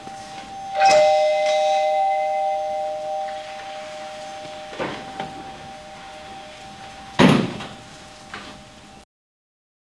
Звуки дверей
Звук звонка в дверь и возня